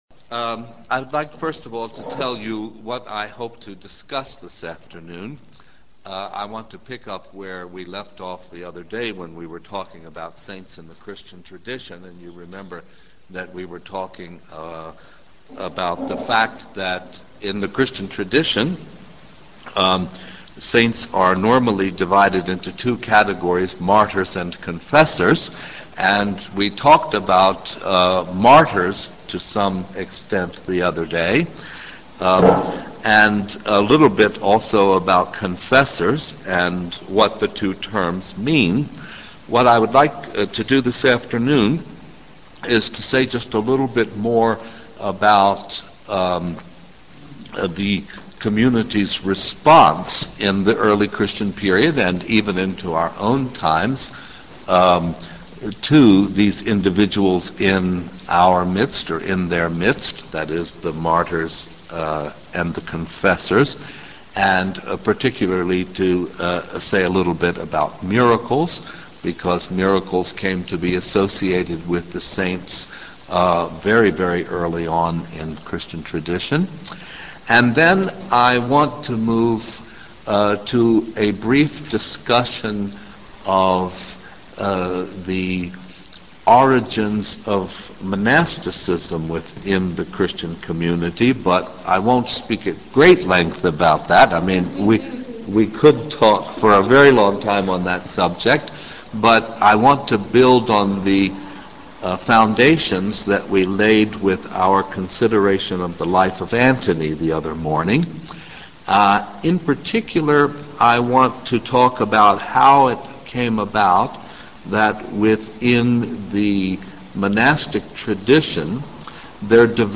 Audio Lectures on topics like interfaith alliance,interfaith christian,interfaith community,interfaith council,interfaith jewish